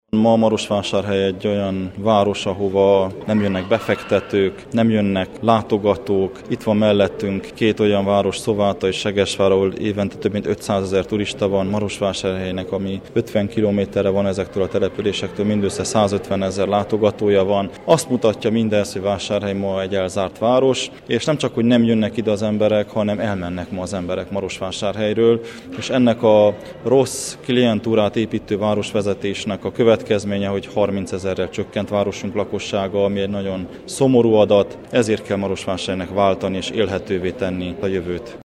Soós Zoltán, a Maros megyei múzeum igazgatója, polgármesterjelölt beszédében hangsúlyozta: Marosvásárhely ma sok szempontból zárt város, fejlődéséhez változásra, nyitott szemléletű új városvezetésre van szükség: